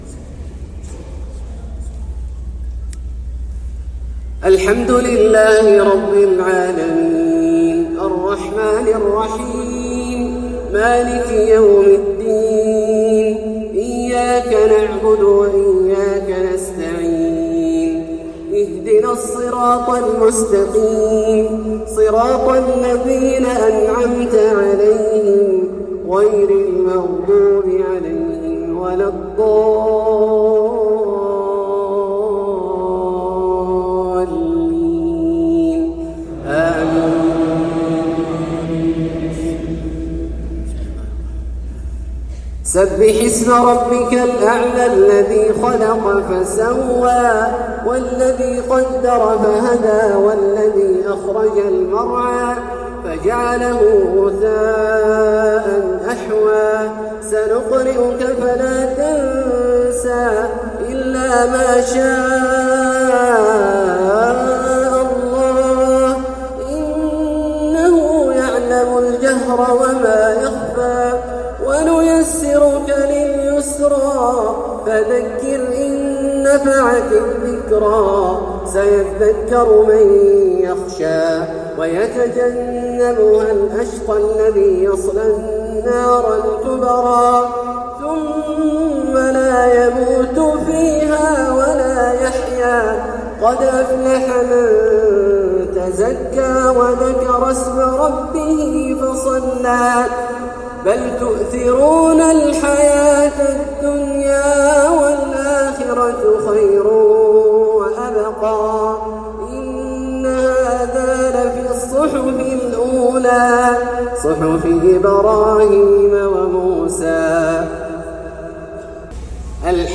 صلاة الجمعة للشيخ عبدالله الجهني من جامع الملك عبدالله بمدينة الملك فيصل العسكرية | 23 محرم 1447هـ > زيارة فضيلة الشيخ أ.د. عبدالله الجهني للمنطقة الجنوبية | محرم 1447هـ > المزيد - تلاوات عبدالله الجهني